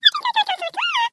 Звук из мультика: герой отчаянно мечтает о чем-то